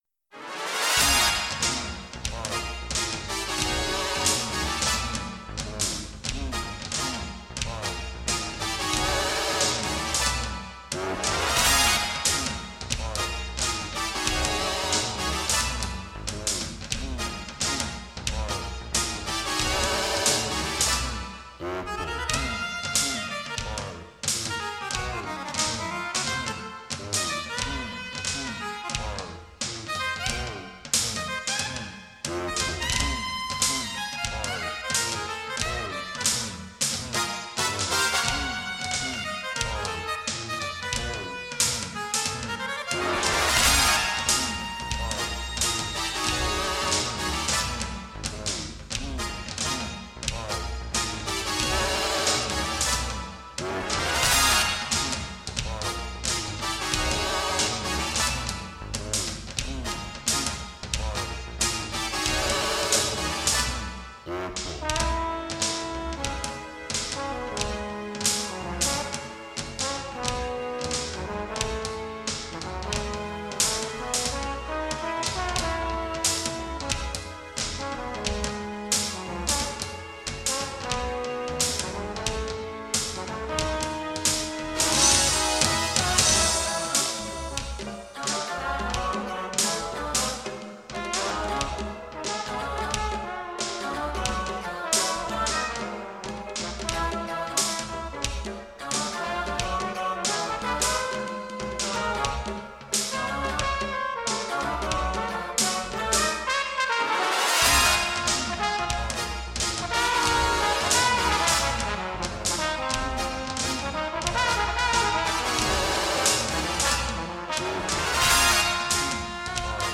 for Electronic music, Video, Laser, Performance and more
なお、残っていた音源がプラネタリウム公演用で、ダイナミクスの幅が広かったものは、若干のコンプ処理をしてあります。
バーティはとても楽しいのですが、その楽しさ故に、やがて、センチメンタルな感情に変わって行くシーンを表現している曲と言えます。
(いい録音が残って無くて、ちょっとヒズんでますが・・・)